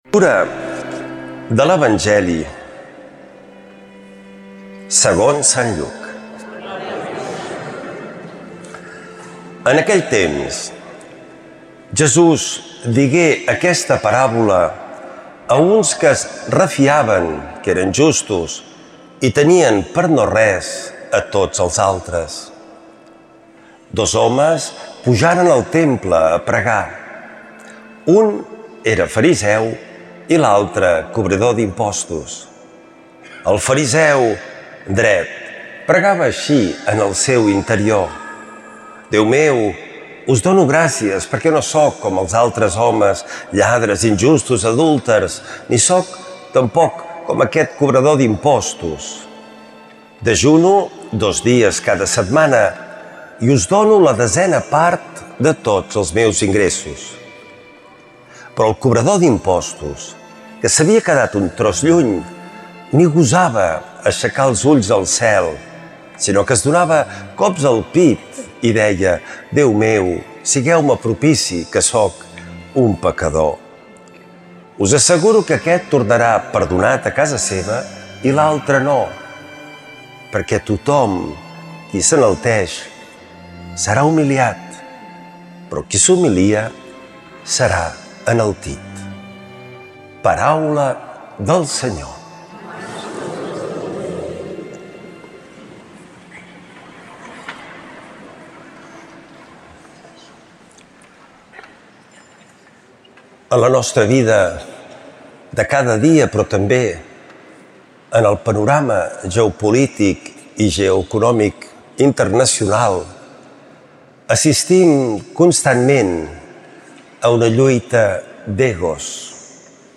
Lectura de l’evangeli segons sant Lluc